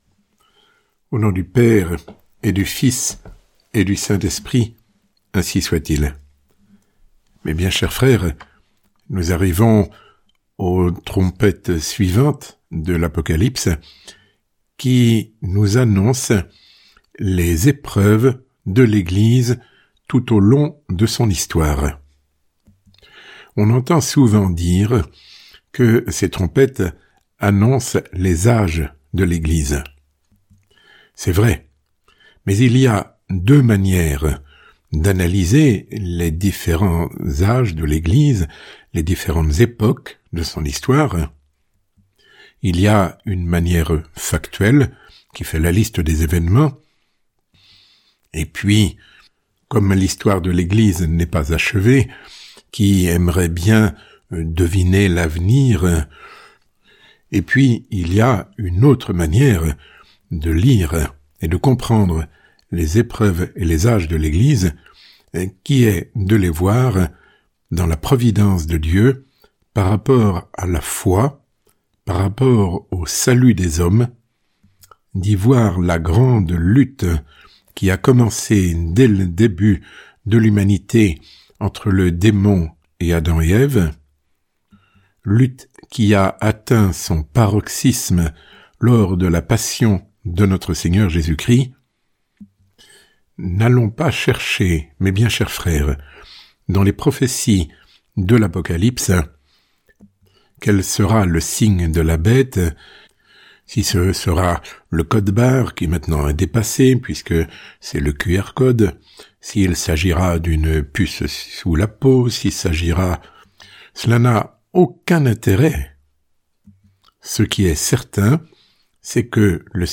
Sermon ~ L’Église à travers l’Apocalypse 216. Les dernières trompettes et les âges de l’Église